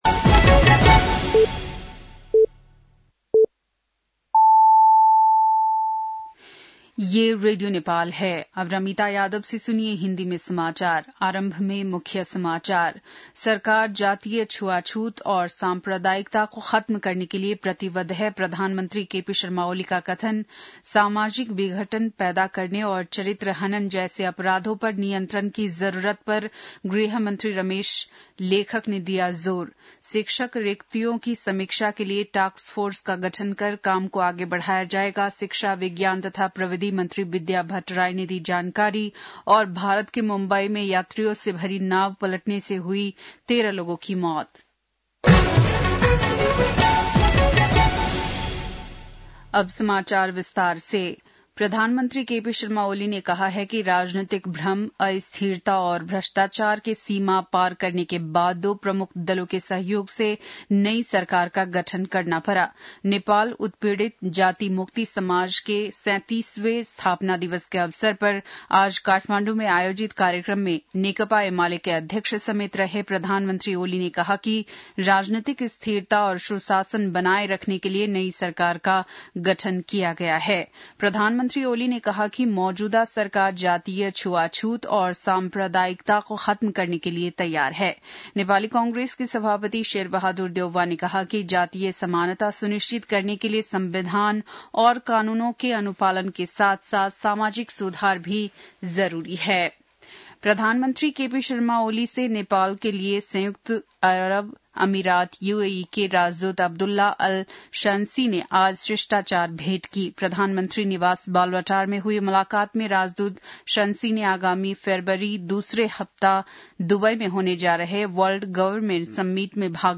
बेलुकी १० बजेको हिन्दी समाचार : ४ पुष , २०८१